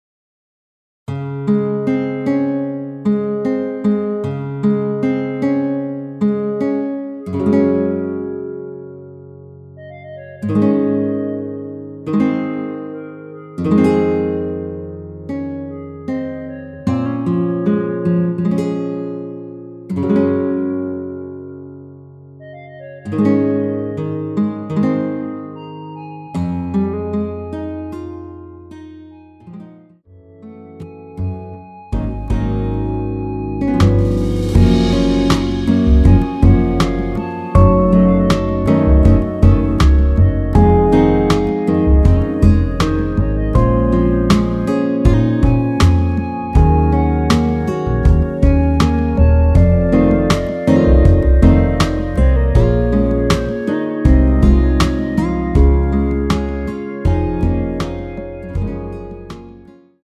전주 없이 시작하는 곡이라 라이브 하기 좋게 전주 2마디 만들어 놓았습니다.(미리듣기 확인)
원키에서(-2)내린 멜로디 포함된 MR입니다.
Db
앞부분30초, 뒷부분30초씩 편집해서 올려 드리고 있습니다.
중간에 음이 끈어지고 다시 나오는 이유는